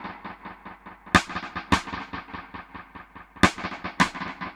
Index of /musicradar/dub-drums-samples/105bpm
Db_DrumsB_EchoSnare_105-02.wav